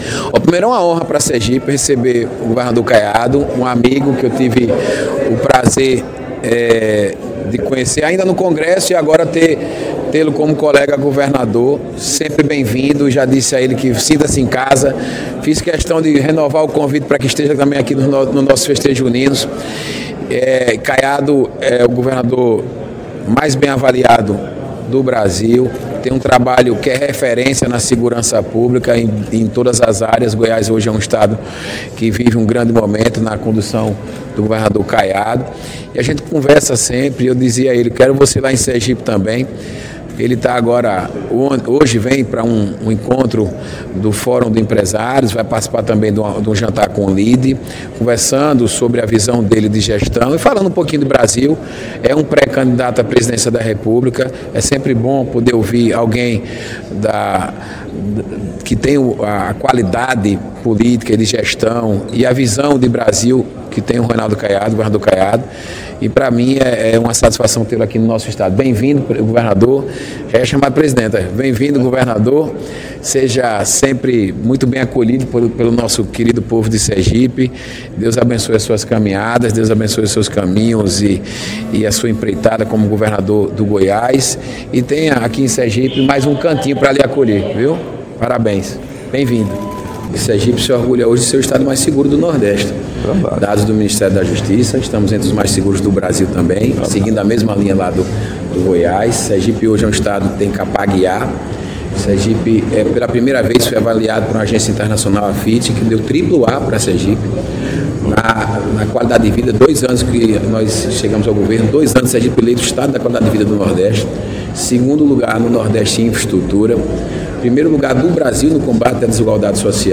Compartilhe FÁBIO MITIDIERI, GOVERNADOR DE SERGIPE. RONALDO CAIADO, GOVERNADOR DE GOIÁS.